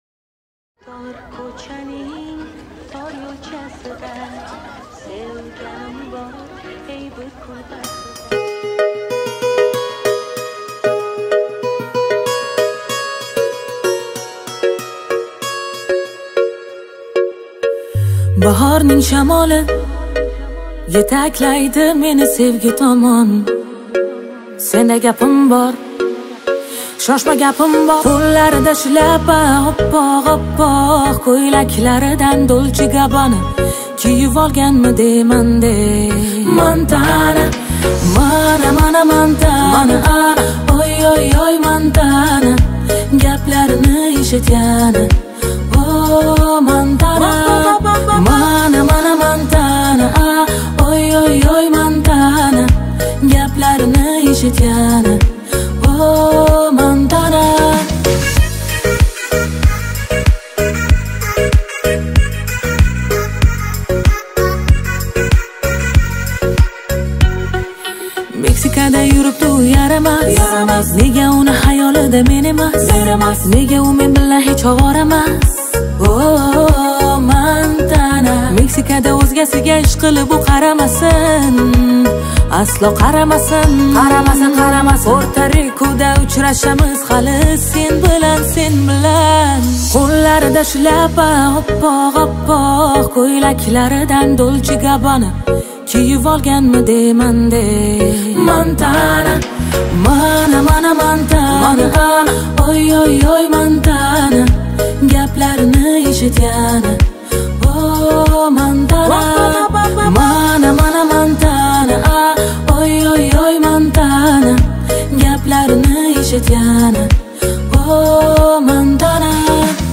Жанр: Узбекские